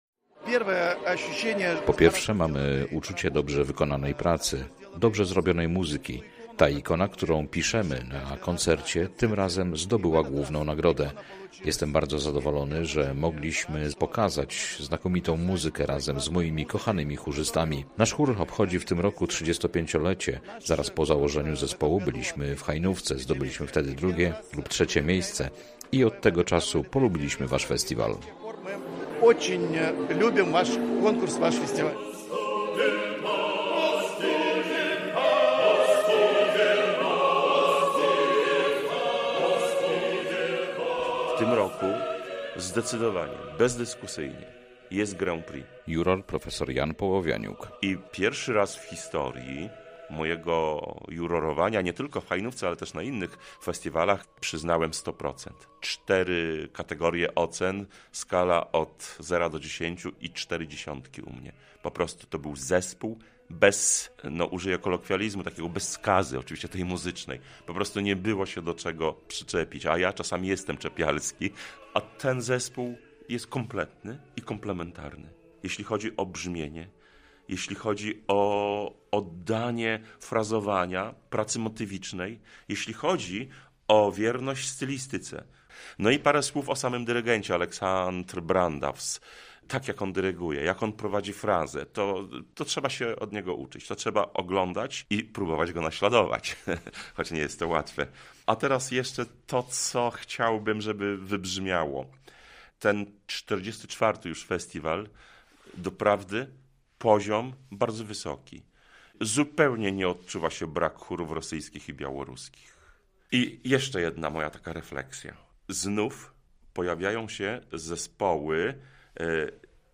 Hajnówka w minionym tygodniu rozbrzmiewała śpiewem cerkiewnym. 23 chóry z kraju i zagranicy prezentowały się podczas 44. Międzynarodowego Festiwalu Hajnowskie Dni Muzyki Cerkiewnej.
Nagrodzone i wyróżnione zespoły zaprezentowały się podczas Koncertu Galowego w Soborze św. Trójcy w Hajnówce.